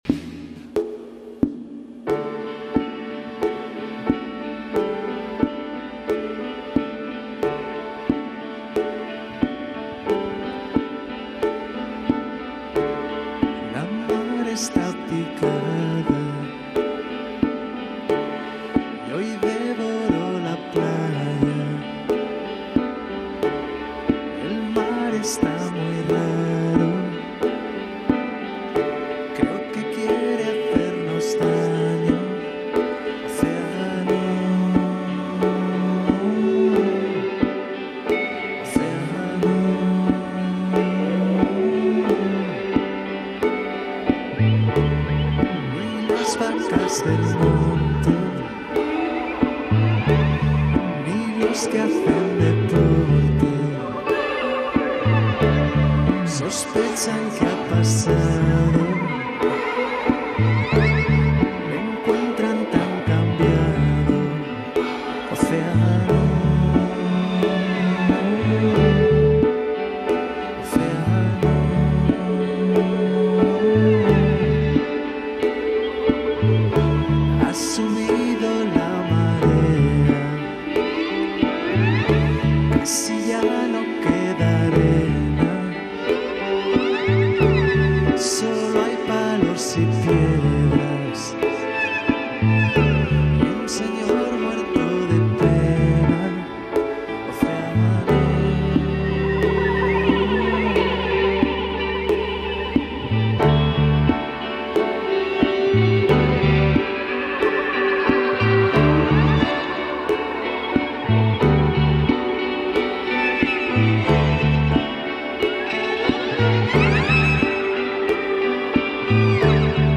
a 7-piece currently based in Madrid
Rock en Español with a breezy twist.